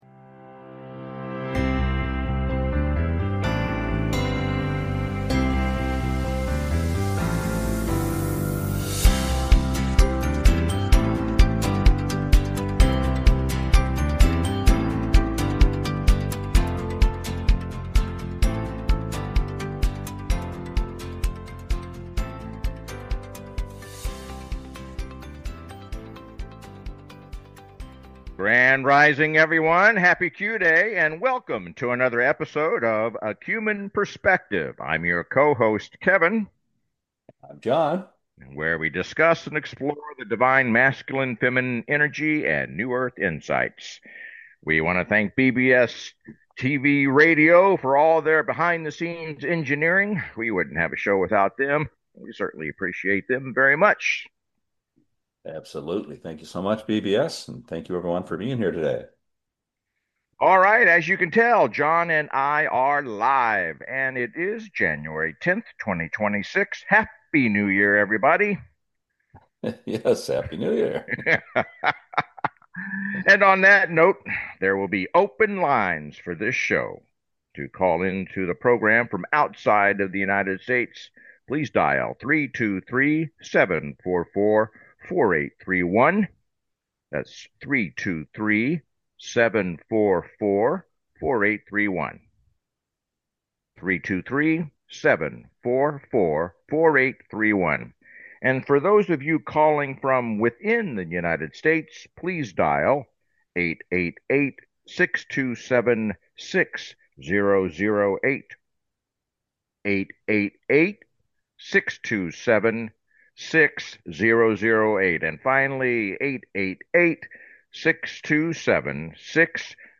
Talk Show Episode, Audio Podcast
The show is structured to welcome call-ins and frequently features special guests, offering a diverse range of perspectives.
The show is not just informative but also entertaining, with humor, jokes, and a whole lot of fun being integral parts of the experience.